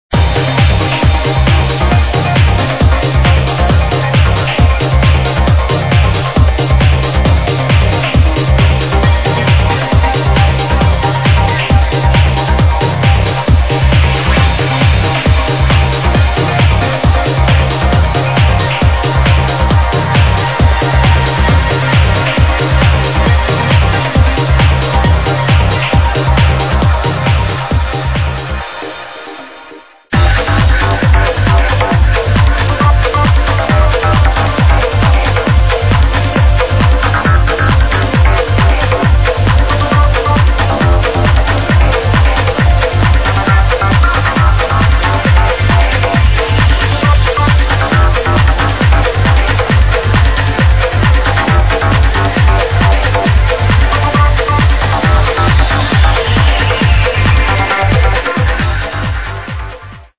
I attached an mp3 file containing 30 secs of each songs.